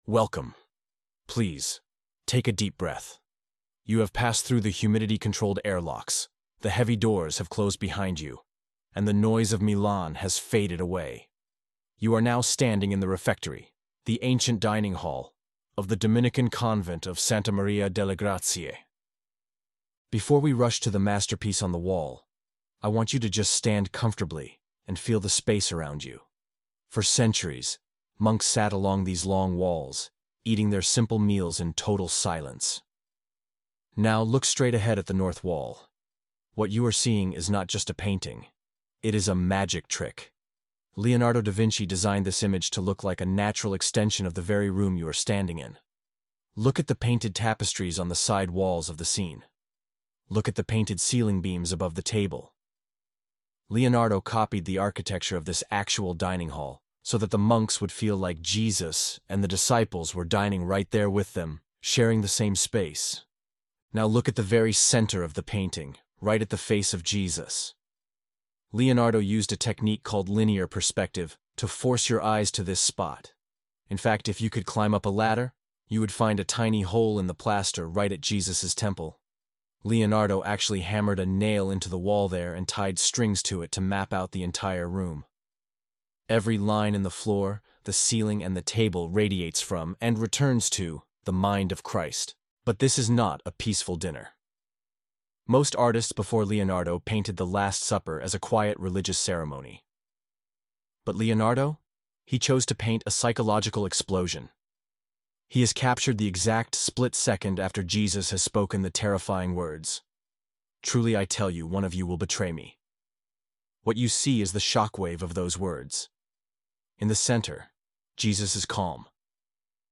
Our Last Supper Audio Guide (Free) - Professional Narration